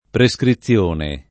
prescrizione [ pre S kri ZZL1 ne ] s. f.